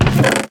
chestopen.ogg